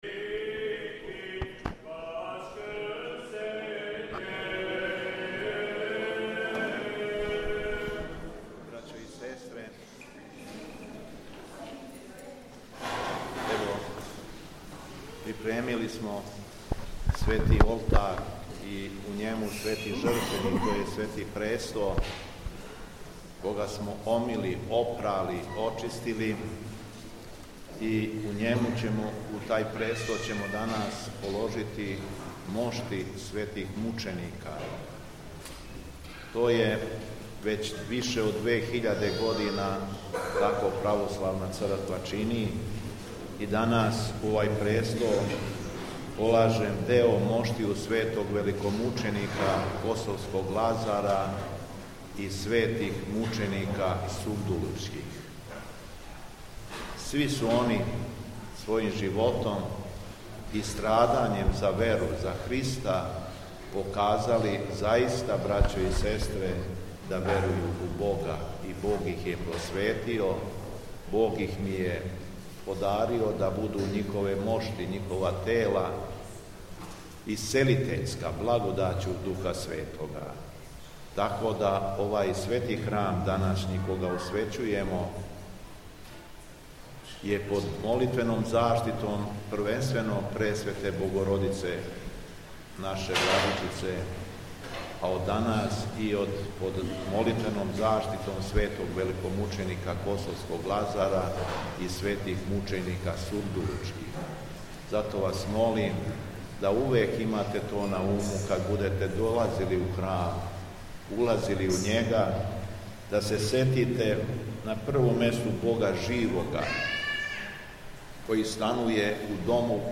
Пред полагање светих моштију у часну трпезу, Митрополит Јован је поучио верни народ:
Духовна поука Његовог Високопреосвештенства Митрополита шумадијског г. Јована